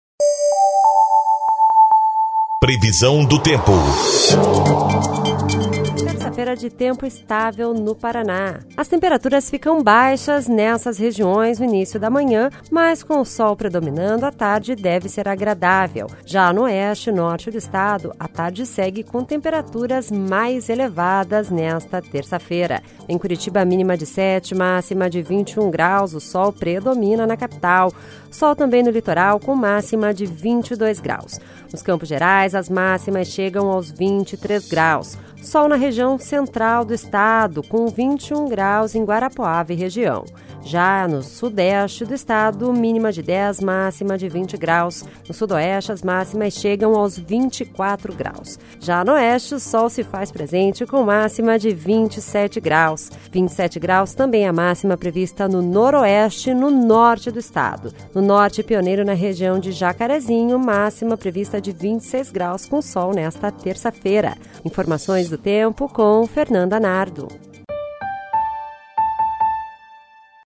Previsão do tempo (01/08)